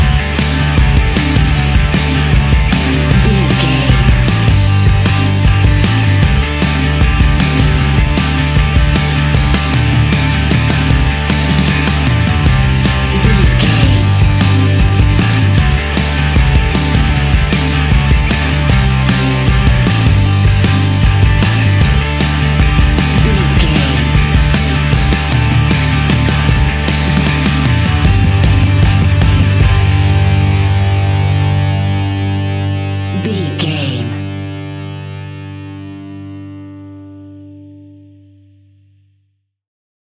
Epic / Action
Fast paced
Ionian/Major
70s
hard rock
blues rock
distortion
instrumentals
Rock Bass
heavy drums
distorted guitars
hammond organ